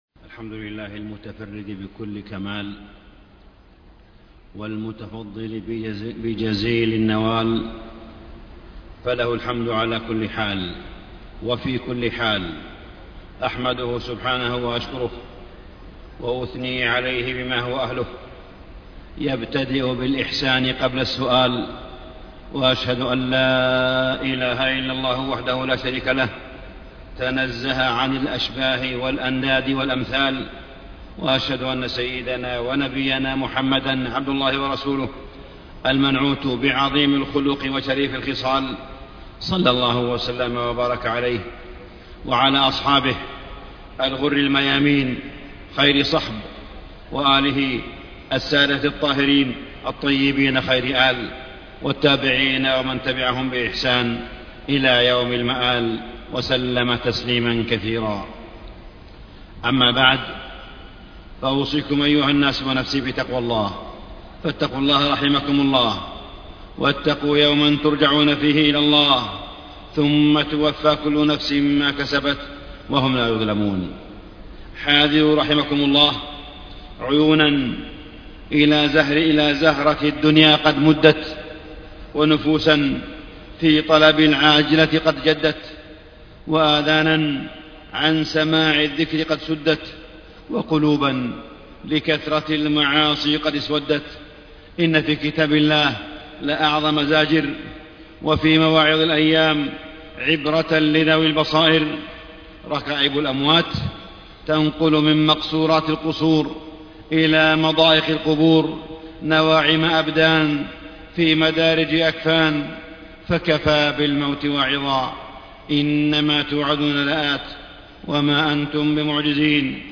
تاريخ النشر ٢٦ شوال ١٤٣٥ هـ المكان: المسجد الحرام الشيخ: معالي الشيخ أ.د. صالح بن عبدالله بن حميد معالي الشيخ أ.د. صالح بن عبدالله بن حميد الفتن والاضطرابات معالم ووقفات